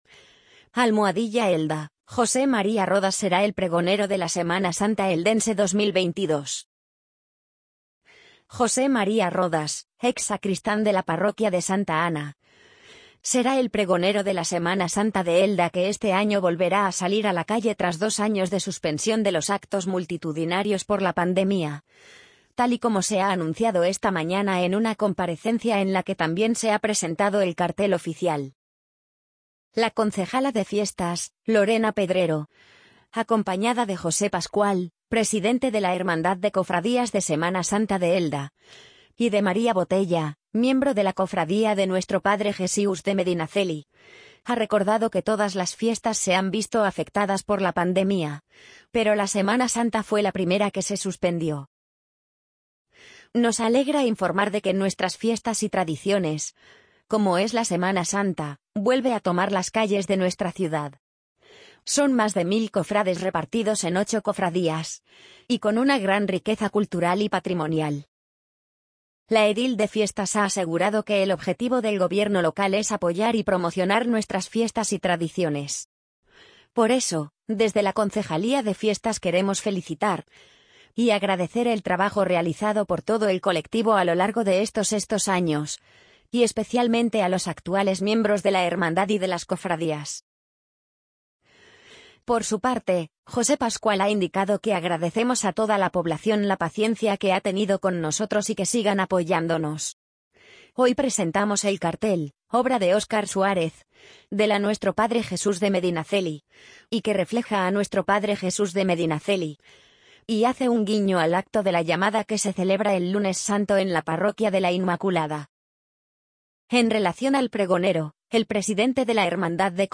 amazon_polly_55345.mp3